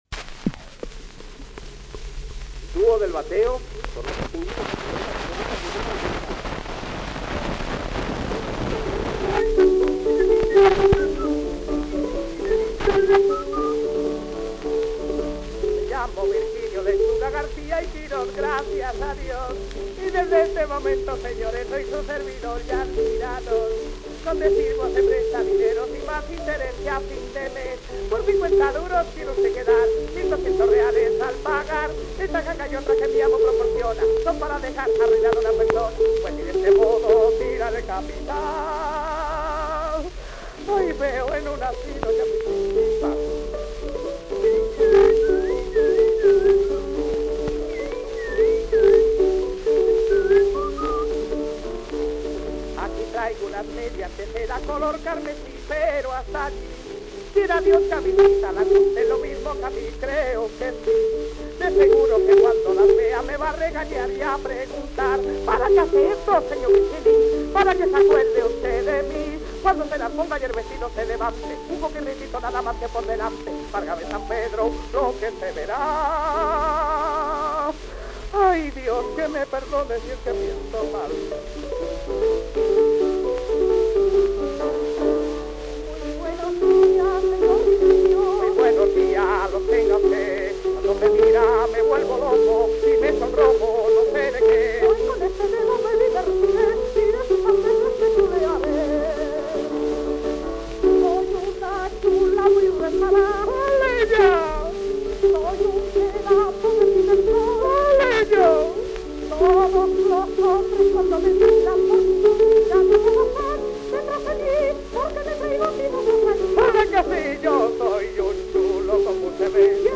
in two parts, i.e. on two cylinders
Tenor cómico active in zarzuela at the dawn of the 20th century in both Madrid and Barcelona.